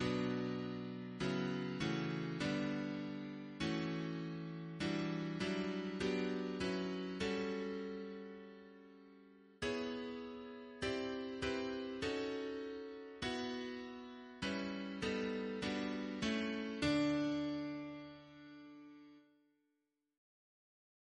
Double chant in D Composer: Ned Rorem (1923-2022) Reference psalters: H1940: 794; H1982: S240